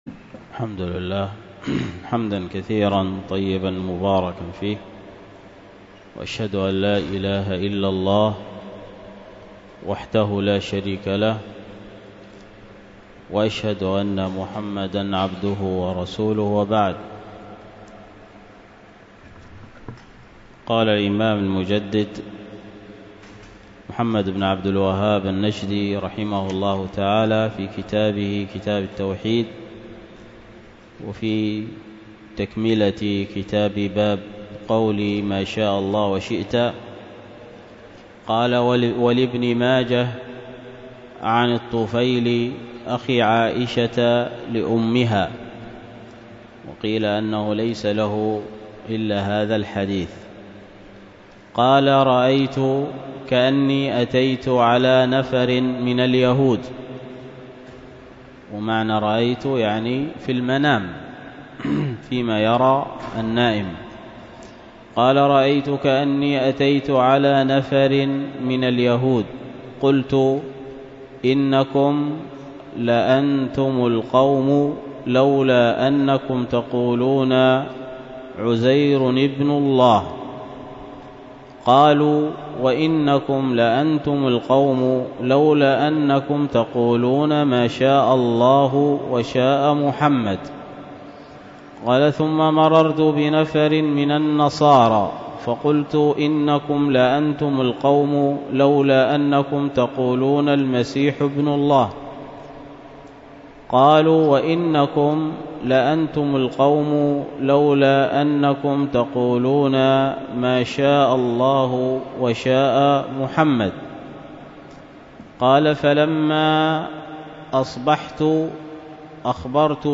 الدرس في أسس قيام الدولة، وكانت بمسجد التقوى بدار الحديث بالشحر